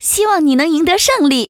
文件 文件历史 文件用途 全域文件用途 Choboong_fw_01.ogg （Ogg Vorbis声音文件，长度0.0秒，0 bps，文件大小：21 KB） 源地址:游戏语音 文件历史 点击某个日期/时间查看对应时刻的文件。